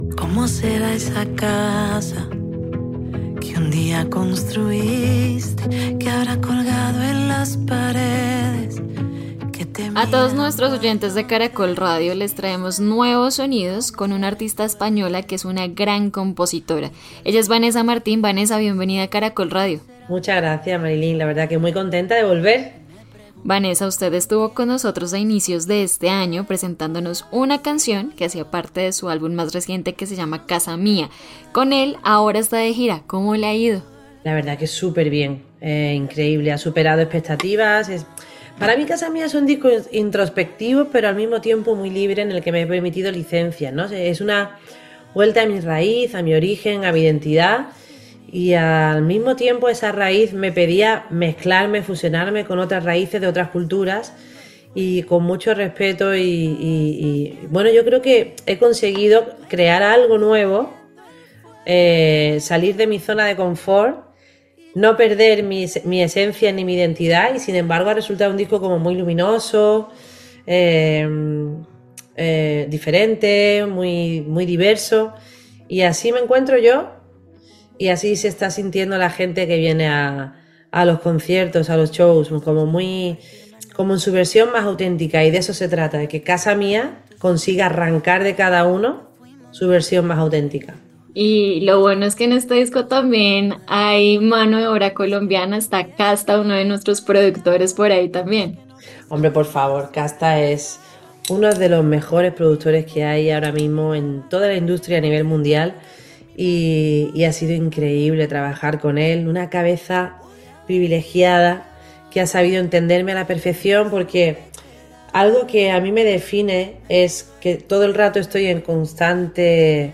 En conversación con Caracol Radio reveló que regresará a Colombia para presentar su nueva música y su más reciente álbum en el mes de mayo.